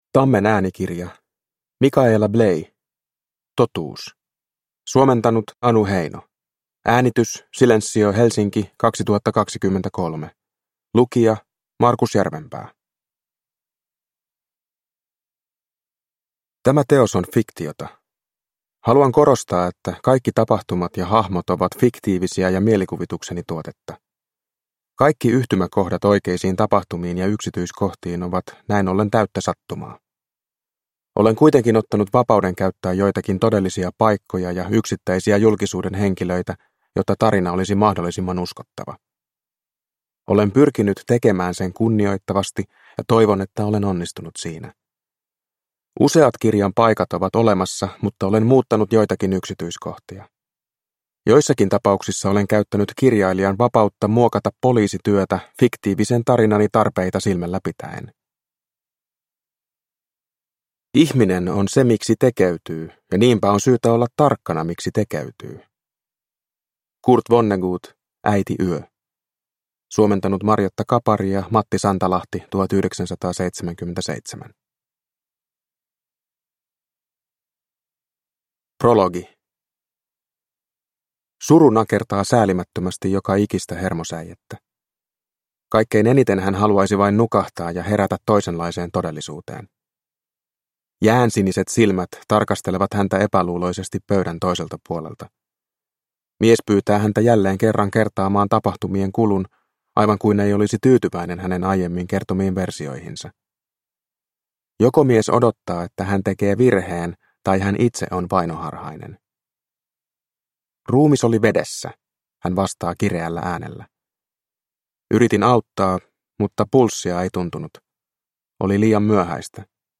Totuus – Ljudbok – Laddas ner